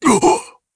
Dakaris-Vox_Damage_jp_02.wav